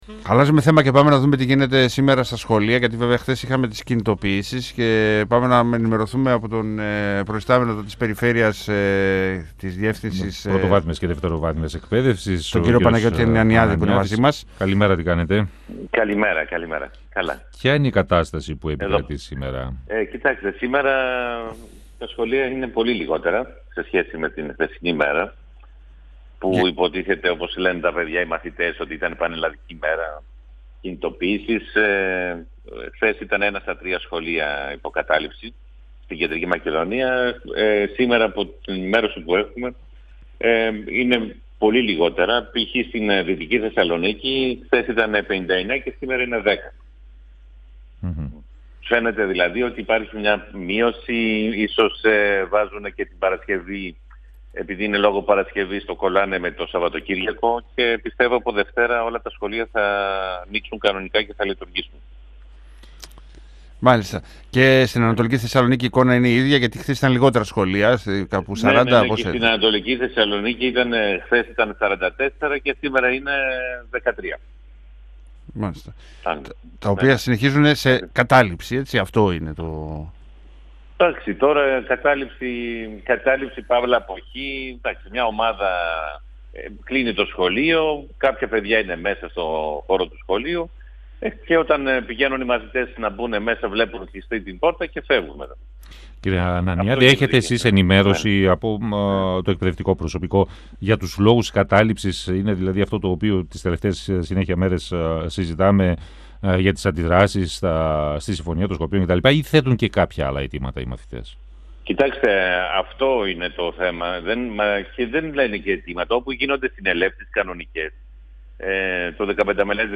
Αισιόδοξος για τις εξελίξεις στο ζήτημα των καταλήψεων σε σχολεία εμφανίστηκε ο επικεφαλής της Περιφερειακής Διεύθυνσης Πρωτοβάθμιας και Δευτεροβάθμιας Εκπαίδευσης στην Κεντρική Μακεδονία, Παναγιώτης Ανανιάδης, μιλώντας στον 102FM της ΕΡΤ3. Ο κ. Ανανιάδης διευκρίνισε ότι μειώθηκε σημαντικά ο αριθμός των υπό κατάληψη σχολείων, ενώ αναφέρθηκε και στην αντιμετώπιση των κενών στην εκπαίδευση με την πρόσληψη περίπου 1.400 εκπαιδευτικών για ανάγκες σε σχολεία της Κεντρικής Μακεδονίας.